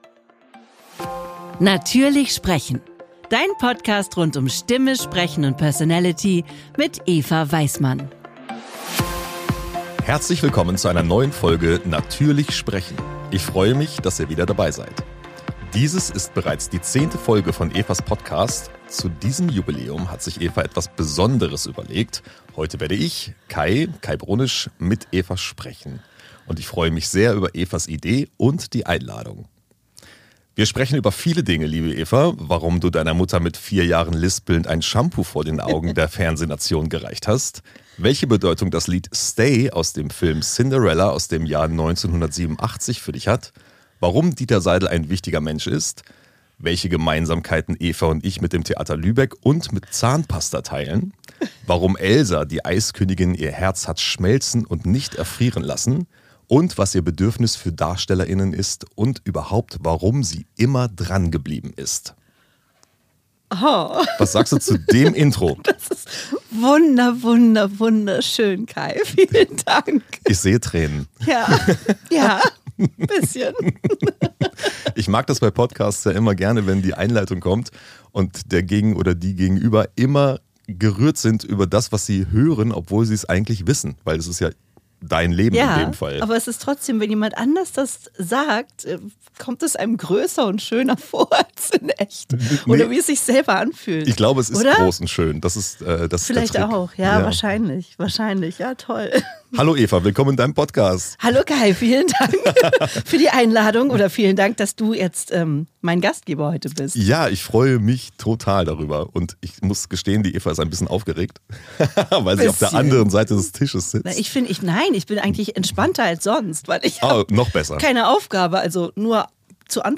Vertauschte Rollen! In dieser Folge werde ich interviewt und erzähle euch, wie ich wurde, was ich bin.